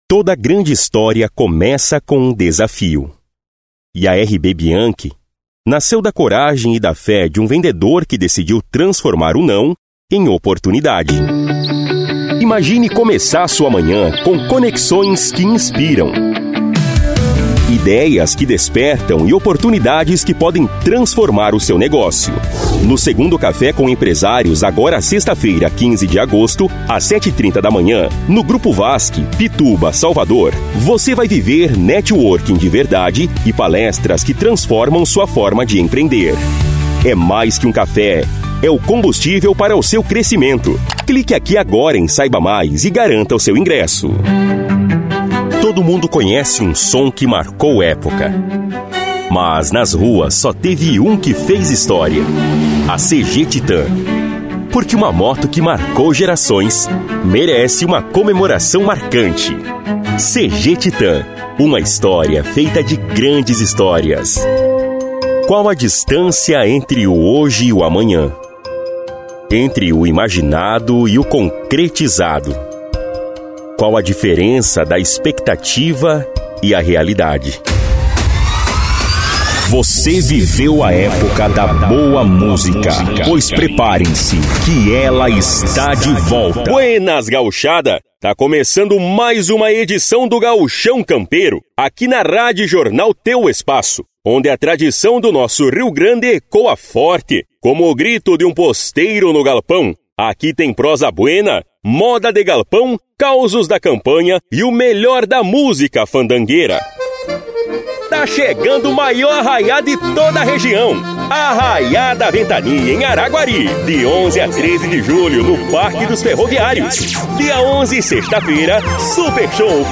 Spot Comercial
Vinhetas
VT Comercial
Espera Telefônica
Impacto
Animada
Caricata